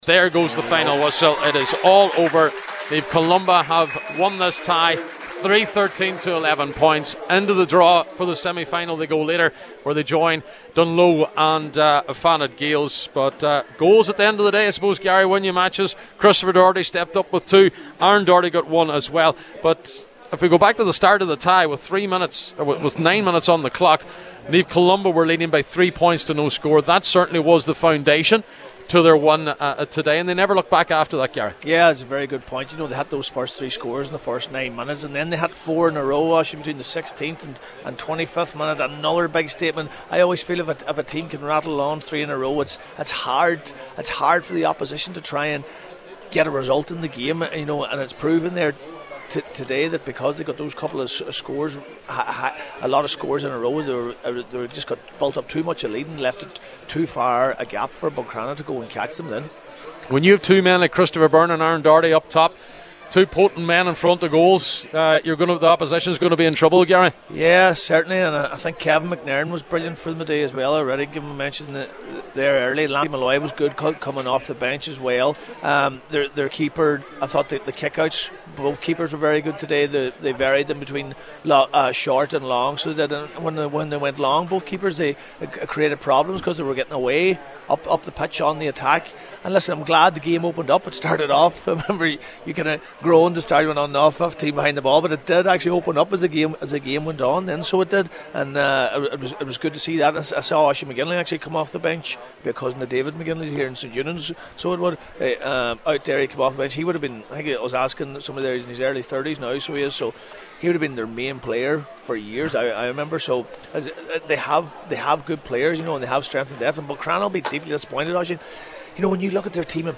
FT Report & Reaction: Naomh Columba beat Buncrana to reach semi final of Donegal IFC